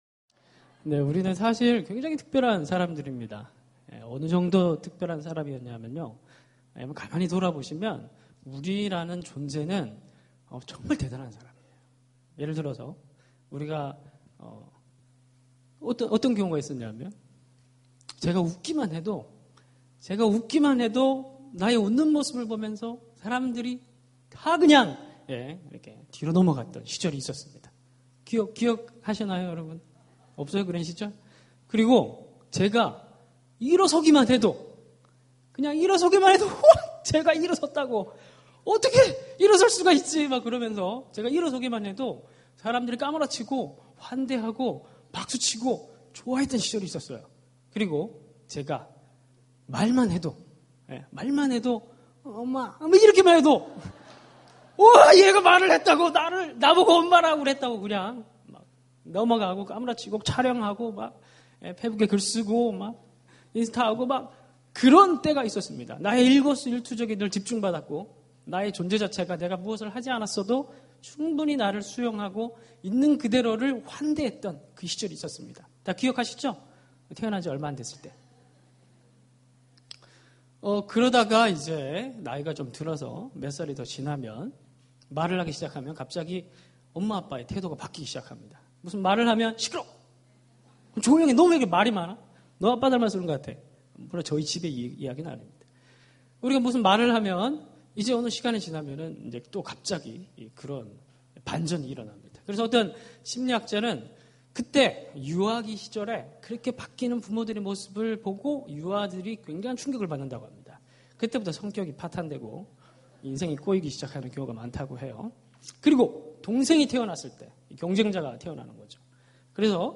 예배 철야예배